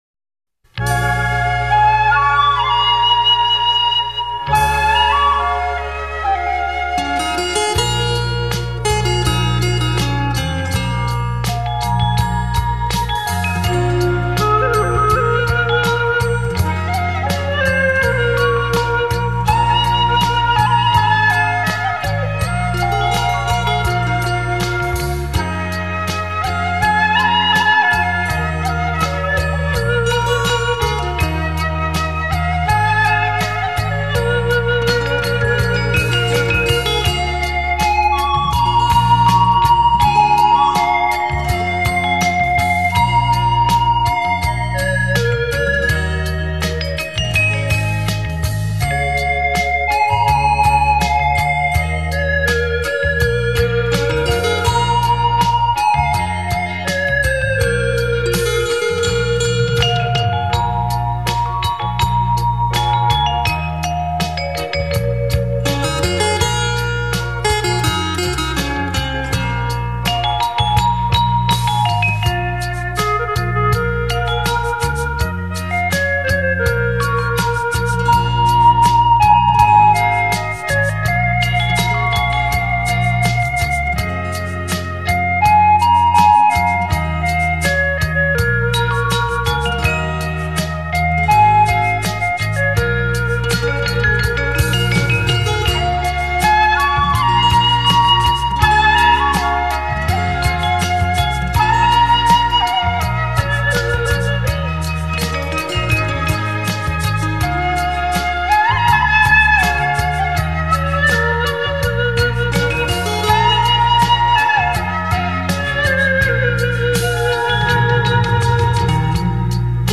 悠揚樂韻  展現純美的音樂質感 , 緩慢 抒情  華麗的旋律
活潑 輕快的技巧性樂緞 , 絲絲入扣  感人至深